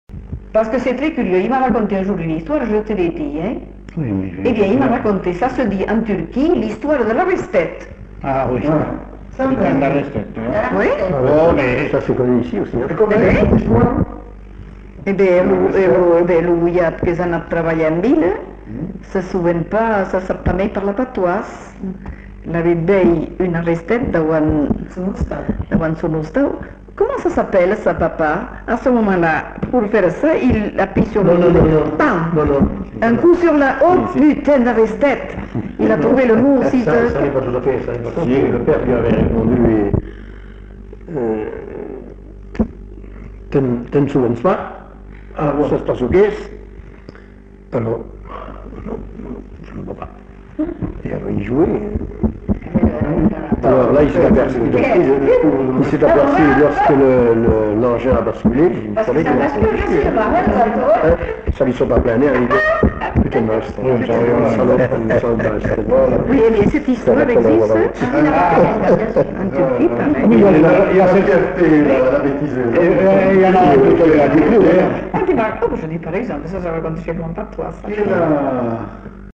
Lieu : Uzeste
Genre : conte-légende-récit
Production du son : parlé
Notes consultables : Raconté par une femme puis complété par un homme qui n'est pas identifié.